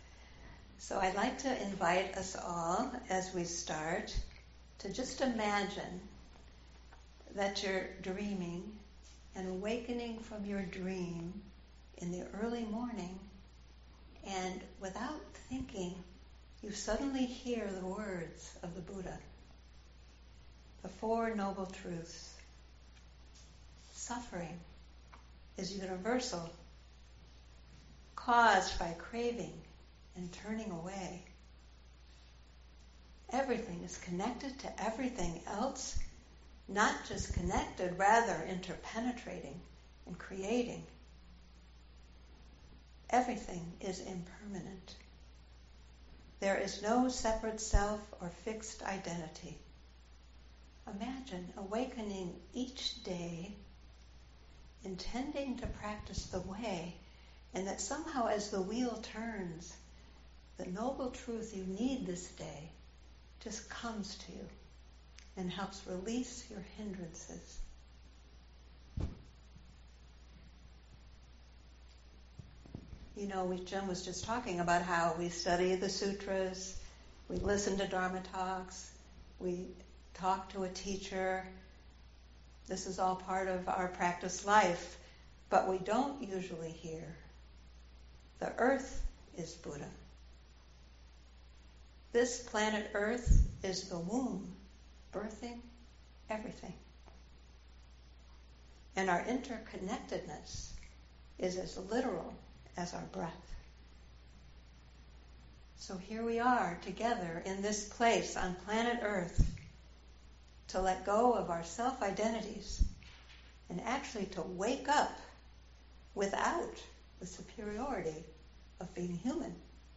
“Earth is Buddha” – Dharma Talk